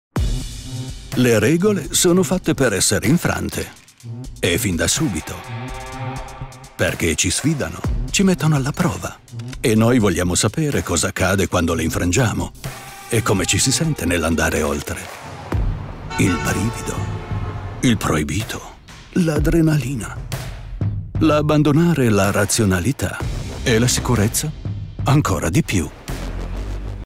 Voice-Age adult Voice-Style deep, middle Country Italien Sex male Voice Probe Italienisch